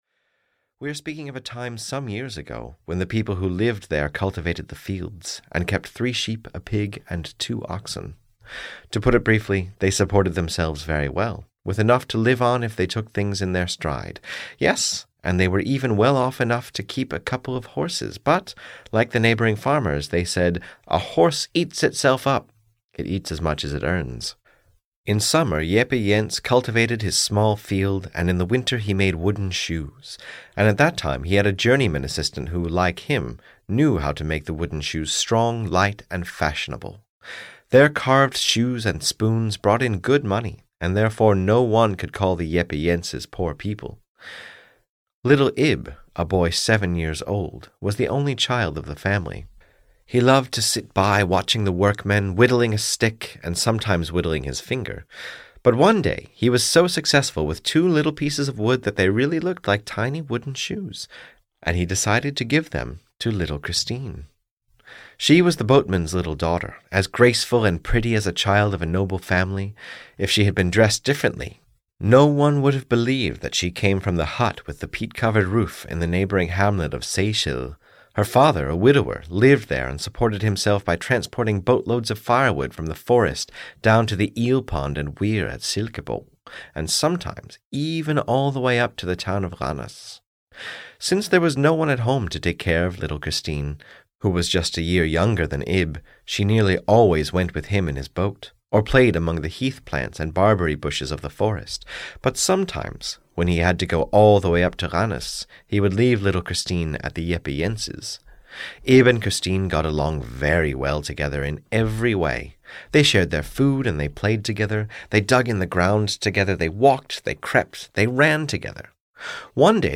Audio knihaIb and Little Christine (EN)
Ukázka z knihy